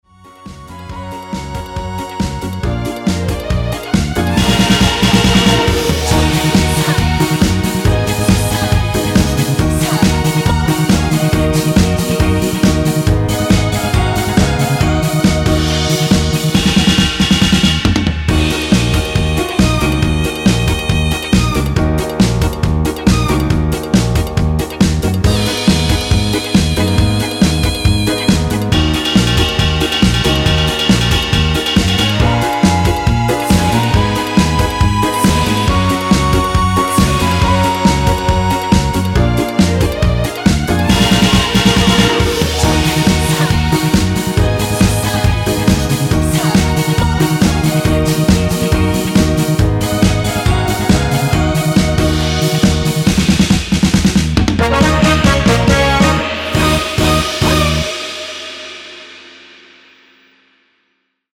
원키 코러스 포함된 MR입니다.
Ebm
앞부분30초, 뒷부분30초씩 편집해서 올려 드리고 있습니다.
중간에 음이 끈어지고 다시 나오는 이유는